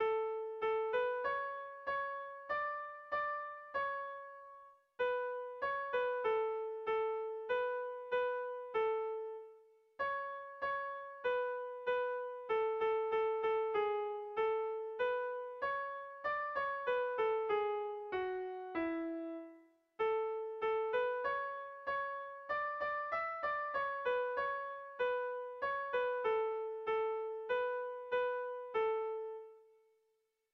Bertso melodies - View details   To know more about this section
Kontakizunezkoa
8A / 7A / 10 / 7A / 10 / 8A (hg) | 8A / 7A / 17A / 18A (ip)
ABD